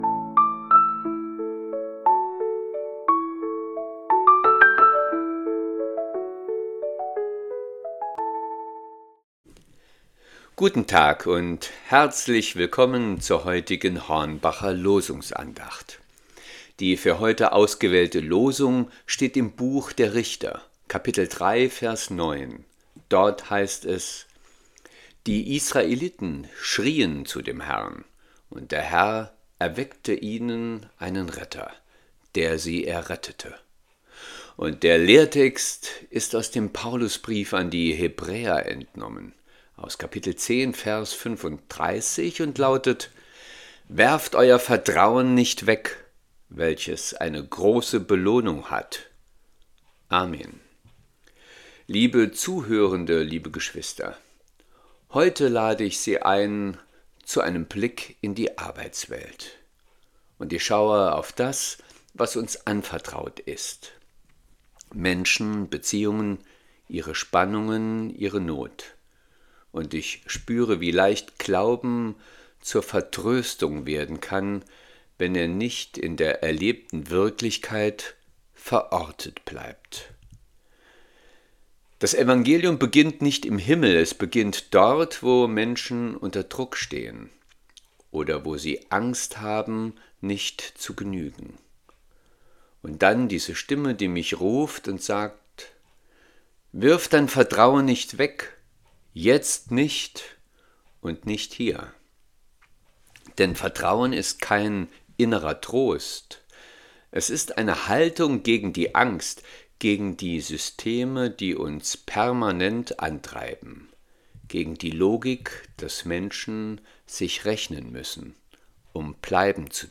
Losungsandacht für Montag, 02.02.2026